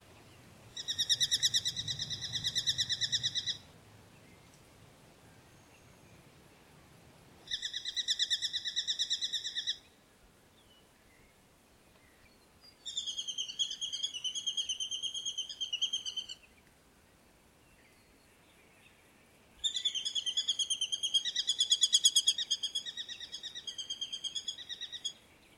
We also recorded the calls of the Sacred Kingfisher and Brown Goshawk – click on the icons to hear them.
Brown Goshawk call:
brown-goshawk.mp3